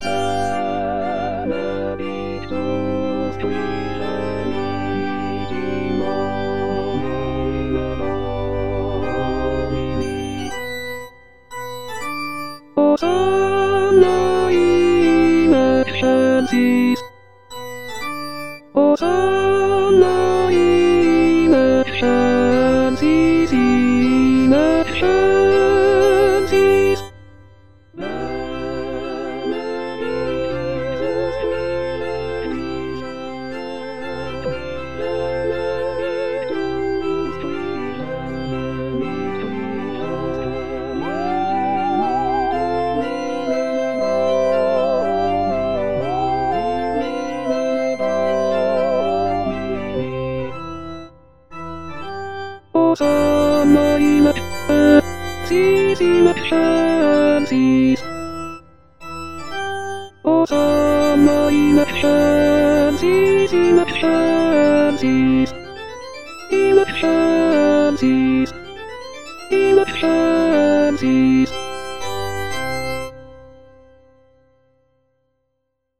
Les aides traditionnelles avec voix de synthèse
Benedictus-Soprano.mp3